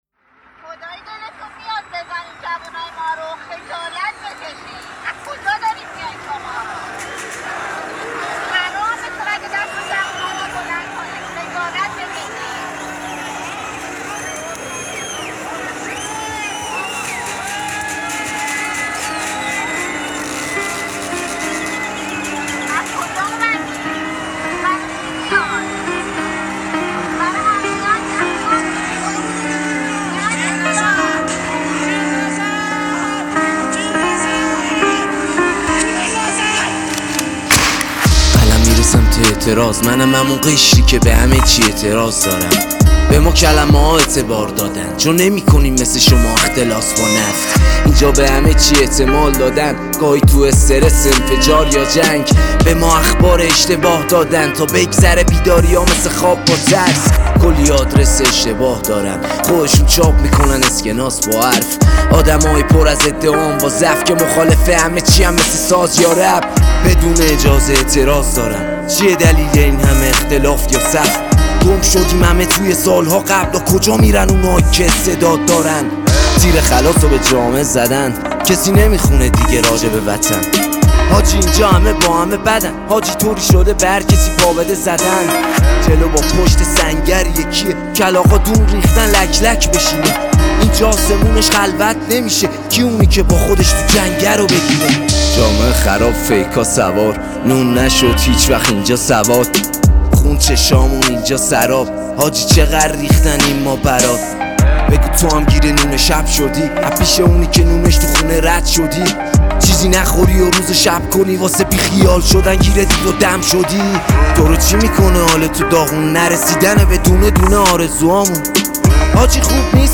سبک：رپ